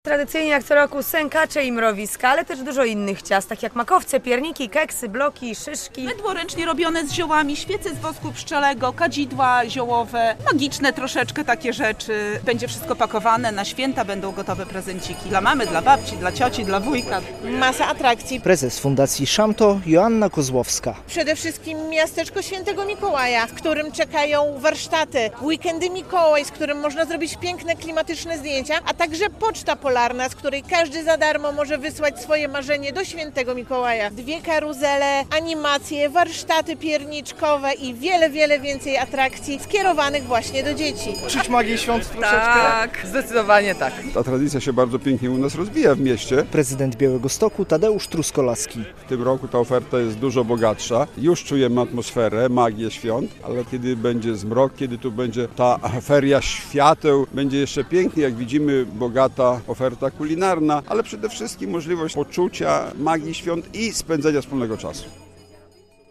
Białostocki Jarmark Świąteczny już otwarty - relacja